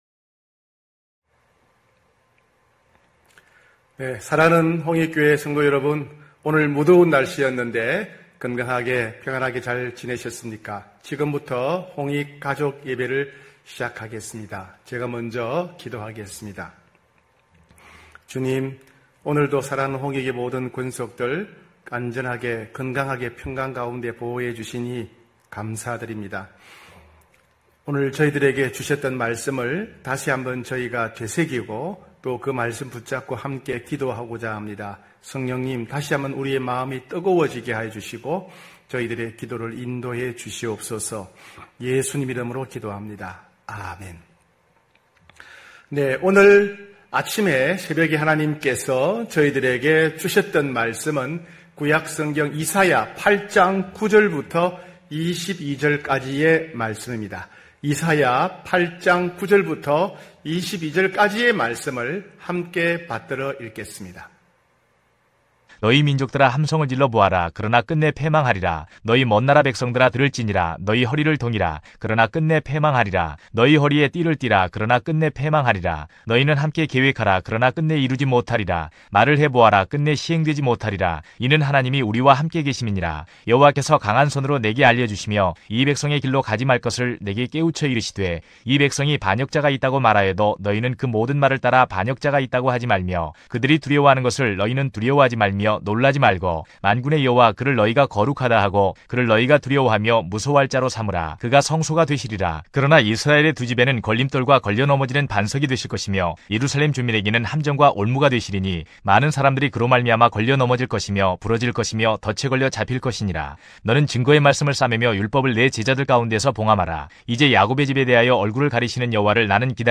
9시홍익가족예배(7월21일).mp3